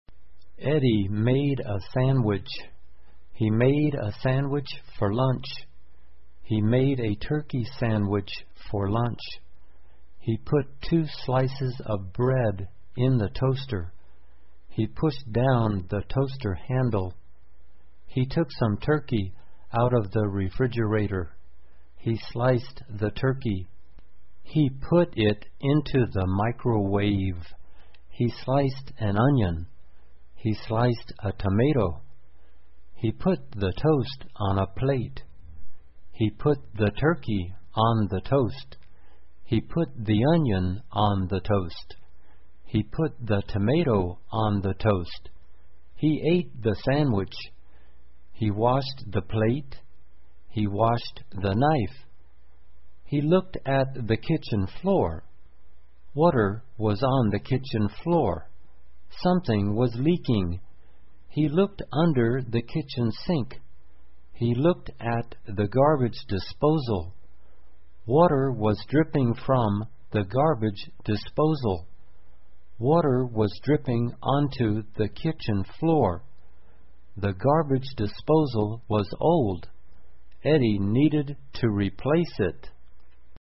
慢速英语短文听力 湿滑的地面(1) 听力文件下载—在线英语听力室